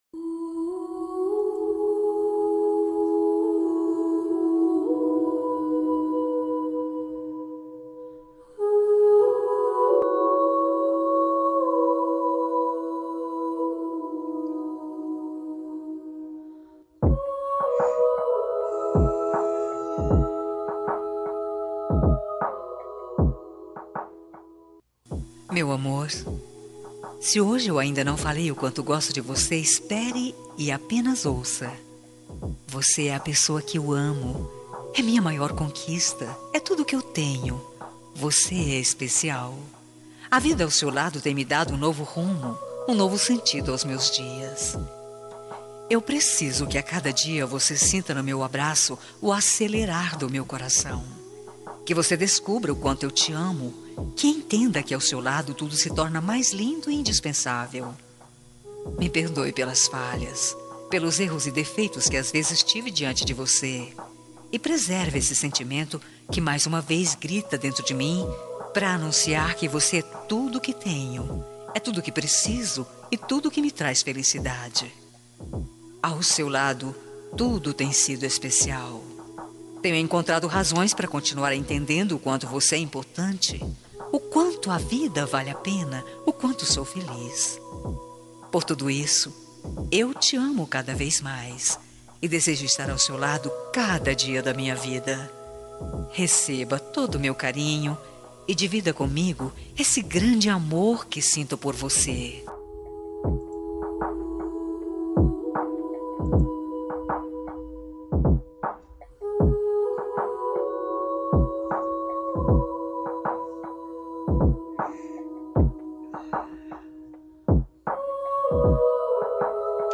Romântica para Marido- Voz Feminina – Cód: 6721